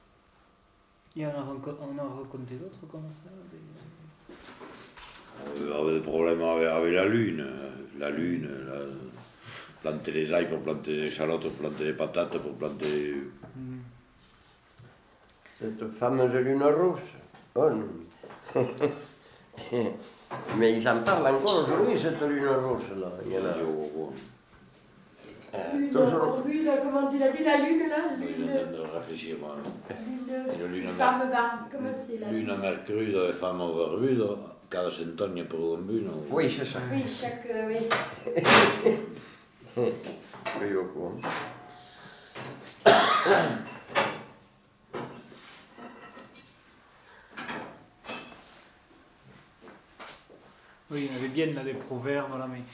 Aire culturelle : Viadène
Genre : forme brève
Effectif : 1
Type de voix : voix d'homme
Production du son : récité
Classification : proverbe-dicton